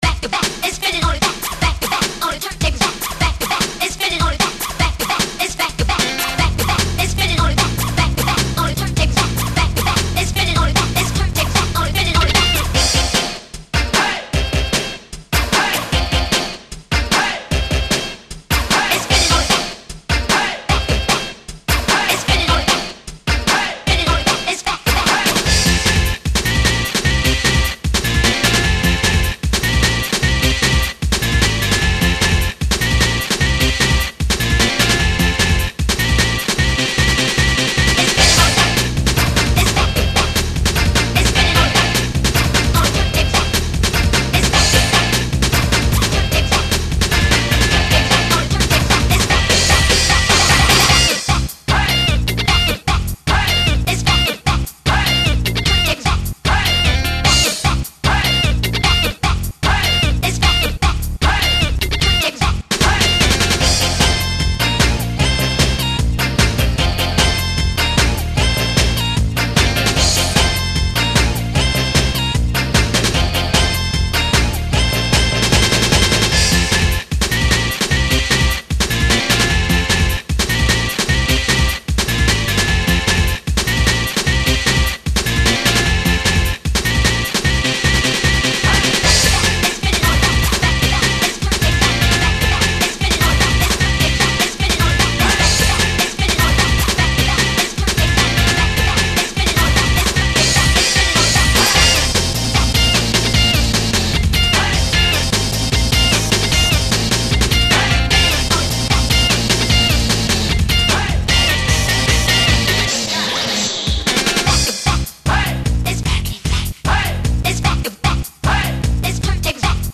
remixes and reinterpretations